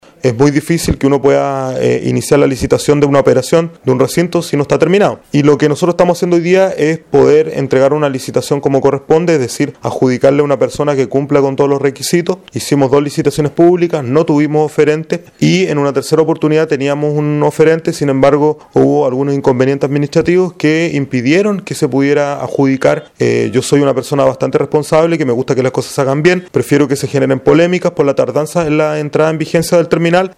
Sobre el problema que mantiene trabado el funcionamiento del terminal, el alcalde Jorge Westermeier señaló que hay algunas dificultades en relación al proceso de licitación pública para su operación.
Cuña-Alcalde-Westermeier-1.mp3